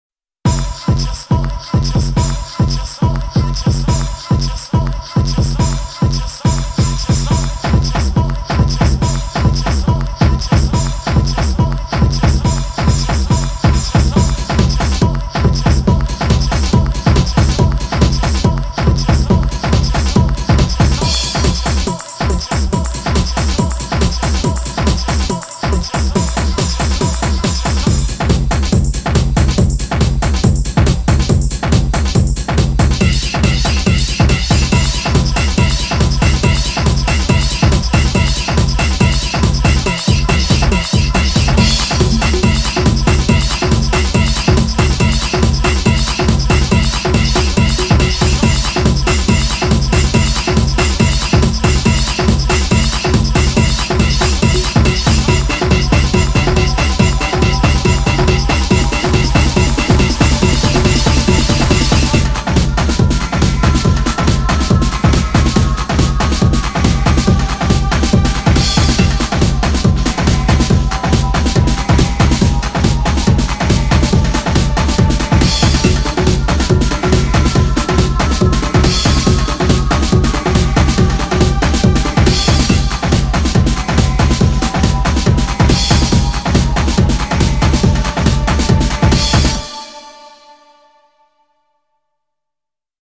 BPM140
Audio QualityPerfect (High Quality)
Comments[HARD TEKNO]